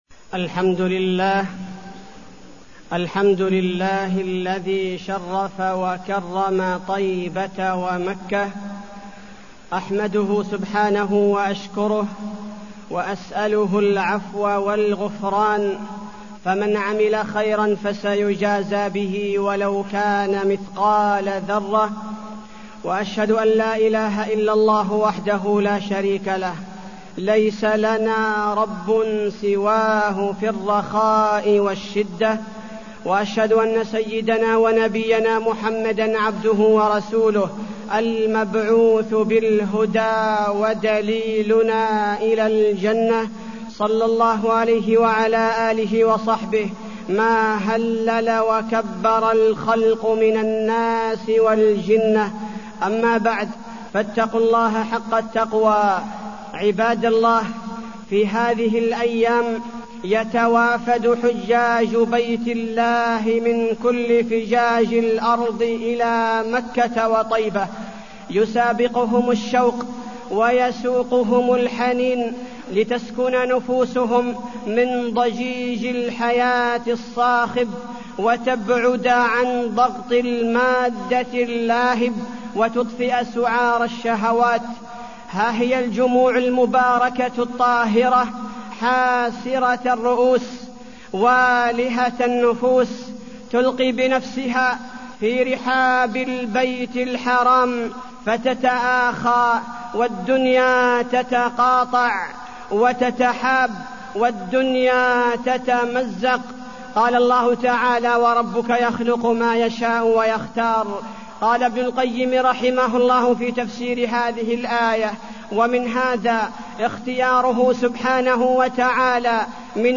تاريخ النشر ١٩ ذو القعدة ١٤٢٠ هـ المكان: المسجد النبوي الشيخ: فضيلة الشيخ عبدالباري الثبيتي فضيلة الشيخ عبدالباري الثبيتي حرمة مكة وفضل المسجد الحرام